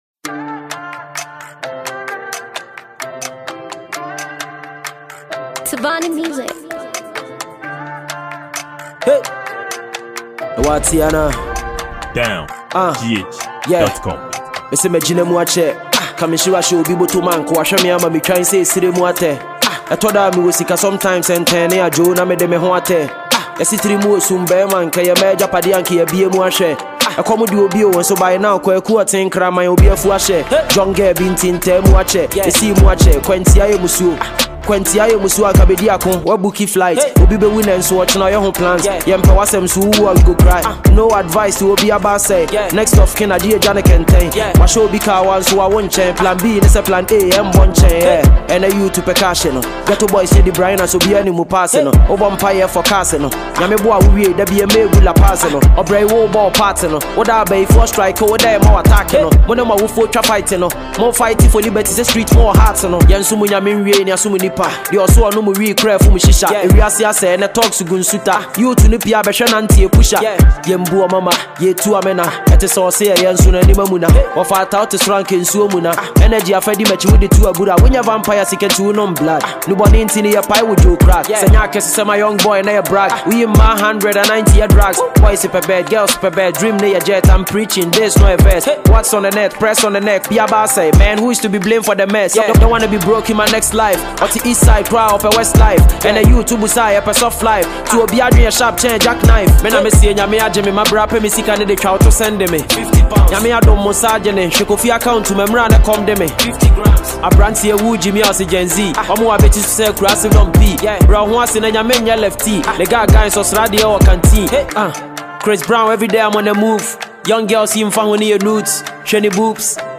Ghanaian rapper, songwriter and musician